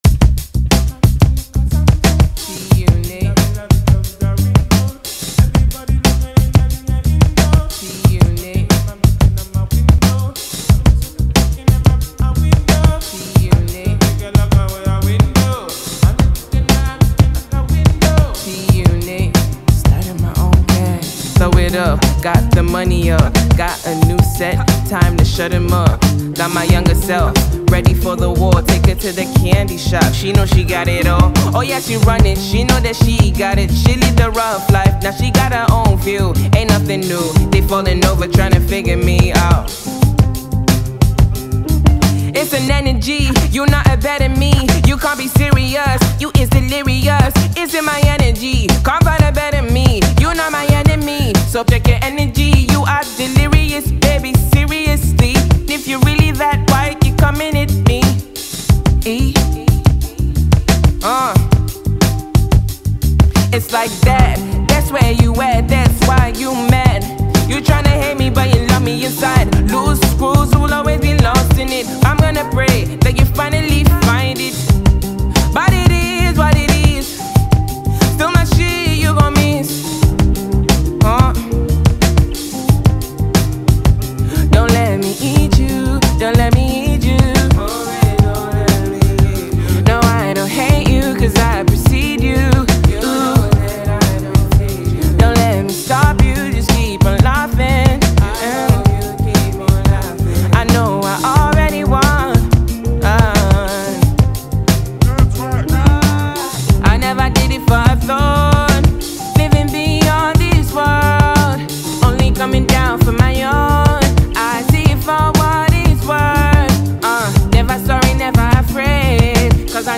Nigerian talented singer and songwriter